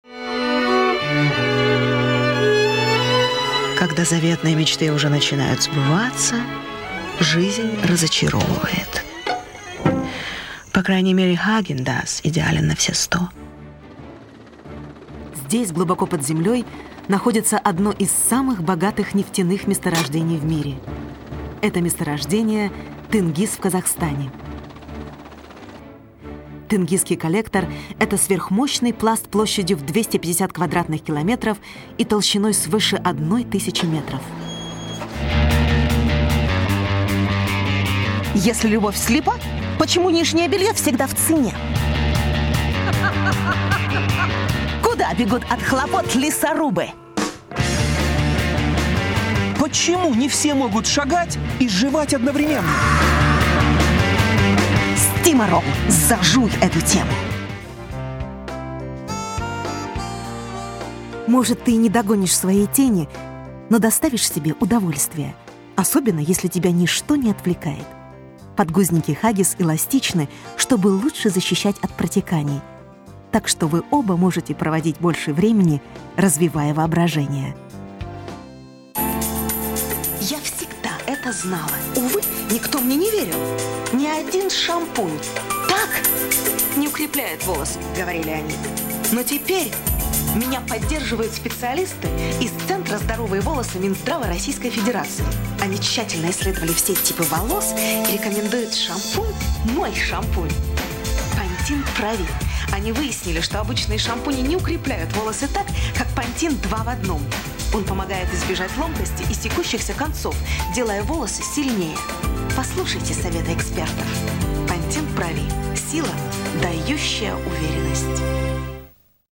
RUSSIAN. Experienced actress and voice artist.
She has since worked extensively in Russian and English, where her smokey Eastern European voice has been used in many films, TV and radio programs, corporate videos and commercials (of course for vodka!).